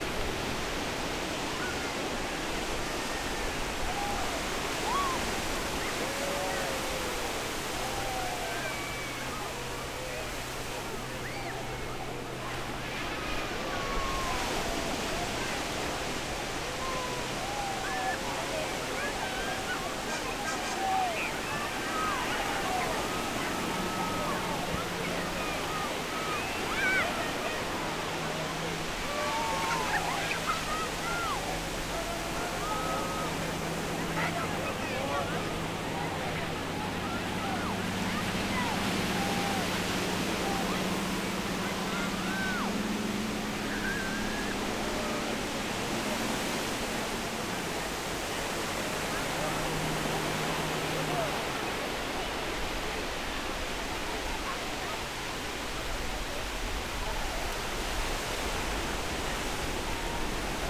Un mapa sonoro es una técnica acústica para conocer los sonidos de un lugar, comunidad o ciudad; ubica los sonidos geográficamente.
Paisaje sonoro Manuel Antonio PUNTARENAS